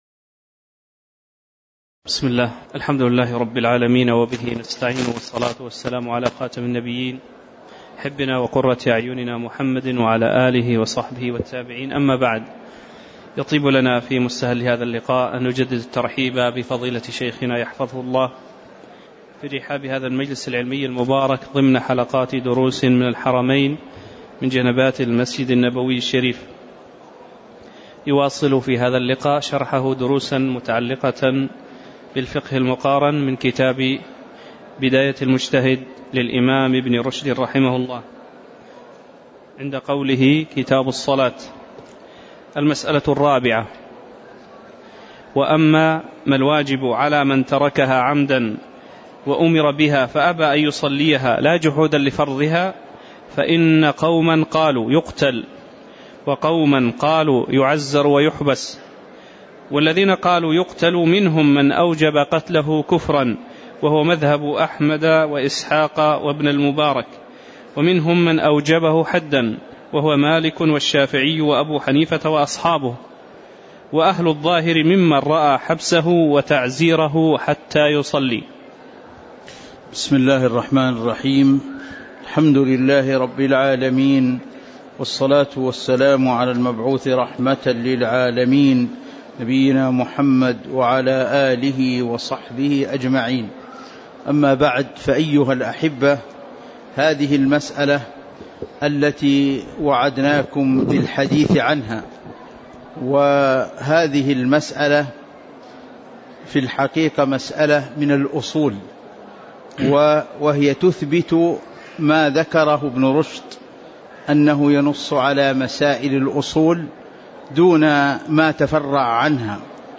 تاريخ النشر ٢٤ محرم ١٤٤١ هـ المكان: المسجد النبوي الشيخ